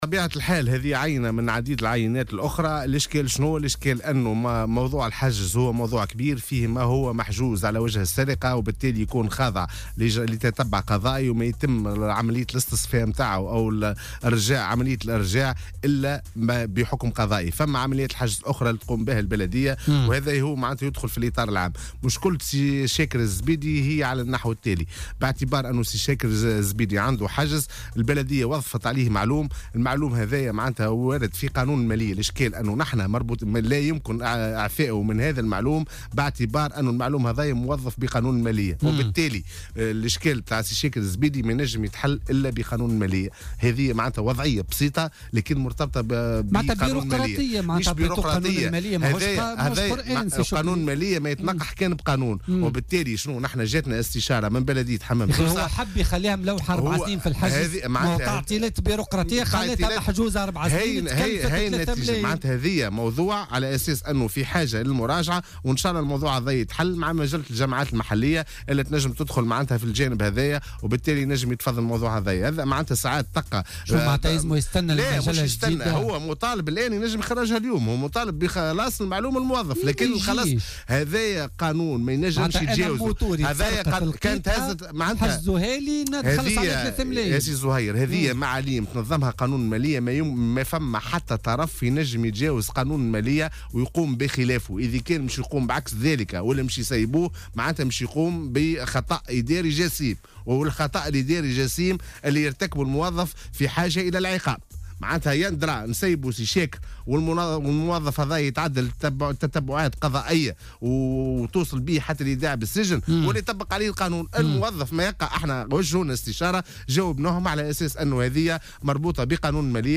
وأوضح في مداخلة له اليوم في برنامج "بوليتيكا" أنه مطالب بخلاص المعلوم الموظف على إيواء الدراجة منذ 4 سنوات، مشيرا إلى أن هذه المعاليم ينظمها قانون المالية وأن تجاوز القانون يعتبر خطأ إداريا جسيما يستوجب العقاب، وفق تعبيره.